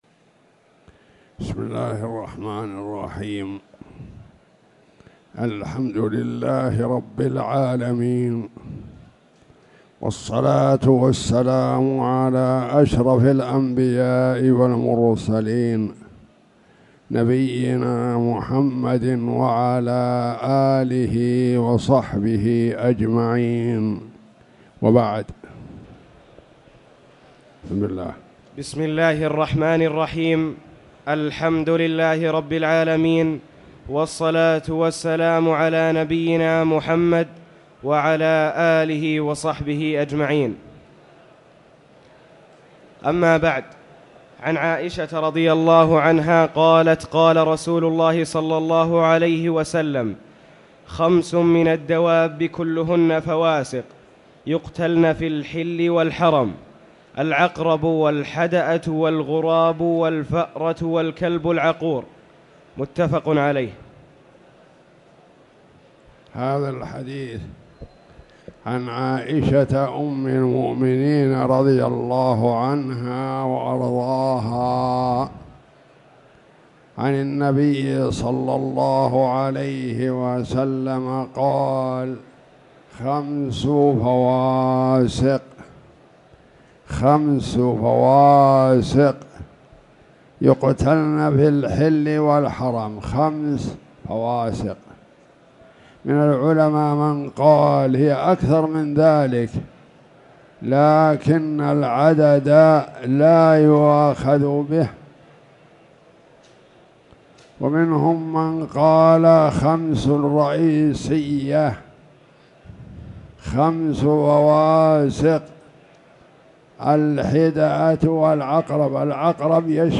تاريخ النشر ١٣ جمادى الآخرة ١٤٣٨ هـ المكان: المسجد الحرام الشيخ